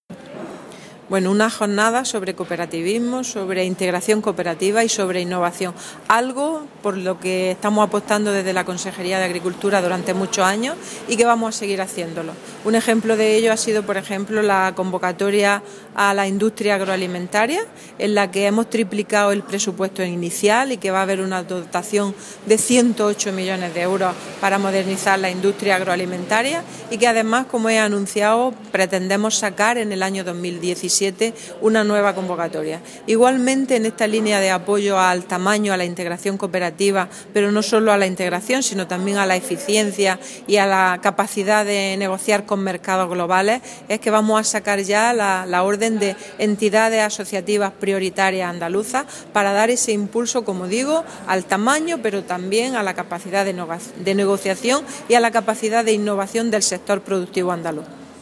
Carmen Ortiz en la inauguración de la jornada ‘Cooperativismo agroalimentario: dimensión y competitividad’ del Instituto de Estudios Cajasol
Declaraciones de Carmen Ortiz sobre apoyo de la Consejería a las industrias agroalimentarias